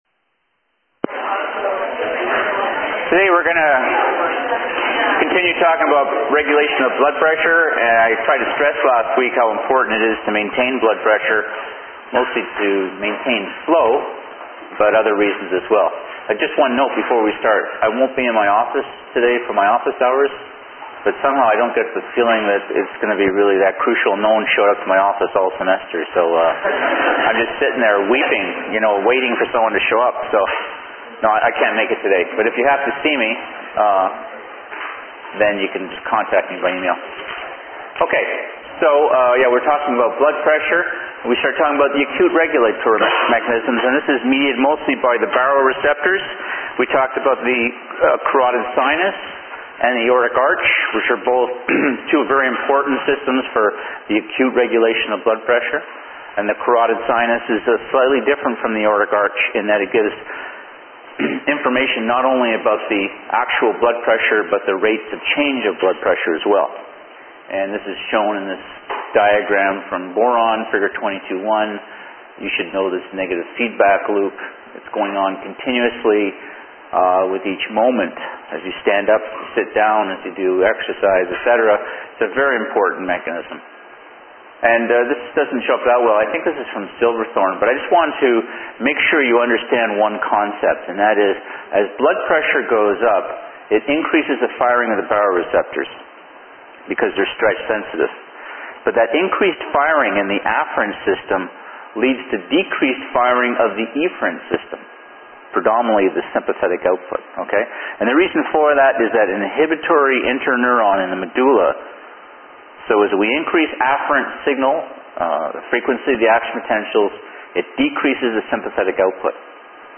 Digital recording of lecture MP3 file requires an MP3 player